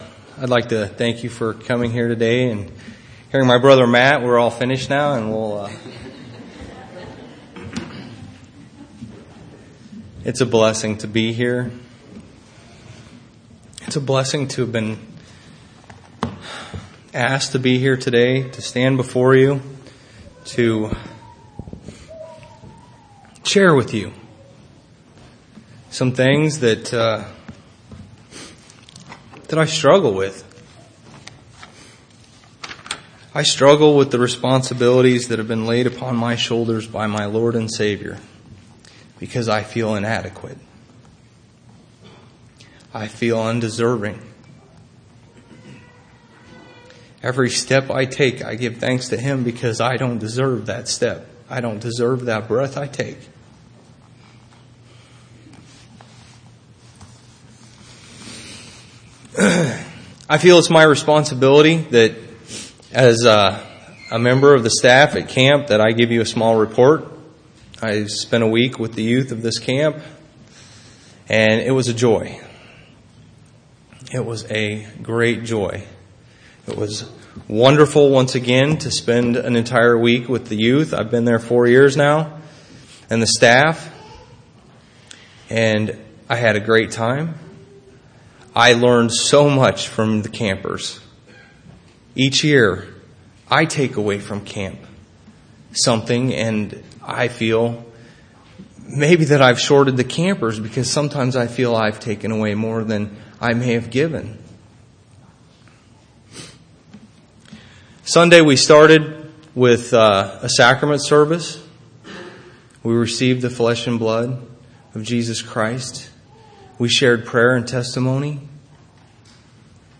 8/1/2009 Location: Missouri Reunion Event